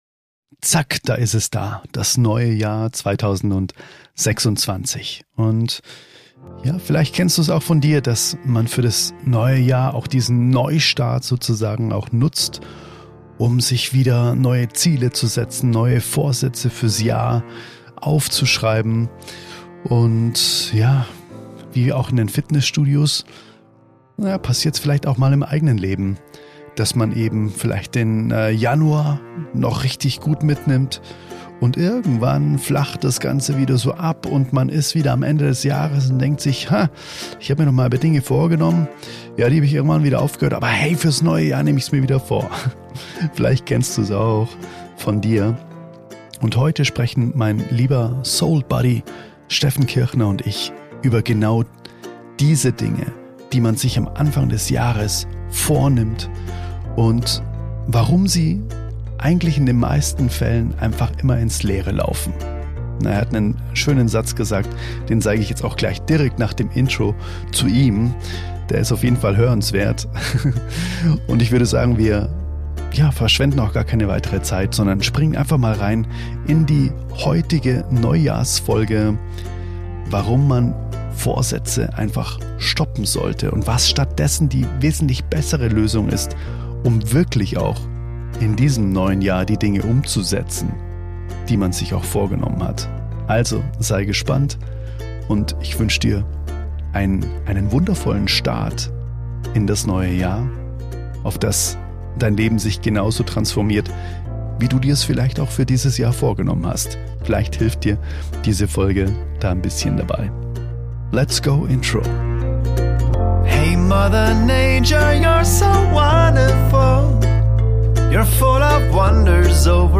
[SOULTALK] Neues Jahr, alte Muster? Wie Du es 2026 anders machst | Gespräch